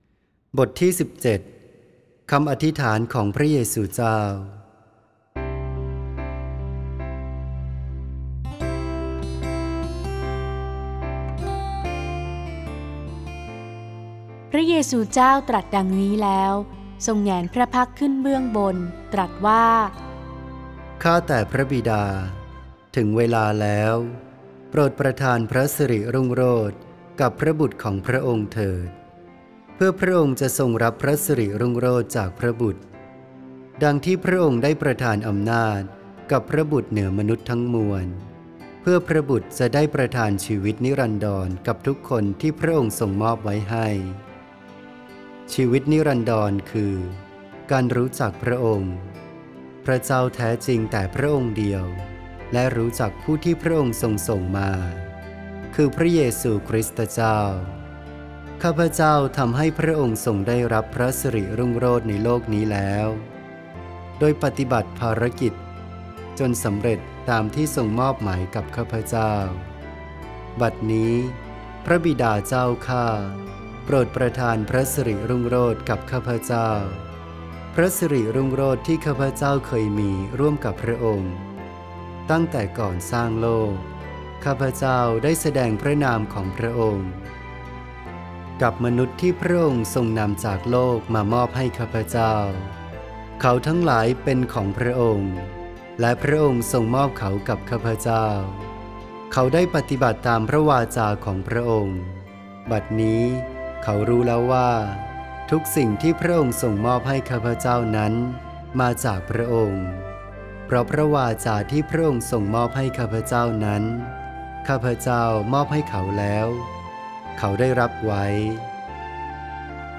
(ไฟล์ "เสียงวรสาร" โดย วัดแม่พระกุหลาบทิพย์ กรุงเทพฯ)